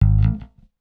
Bass_Stab_06.wav